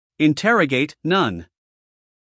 英音/ ɪnˈterəɡeɪt / 美音/ ɪnˈterəɡeɪt /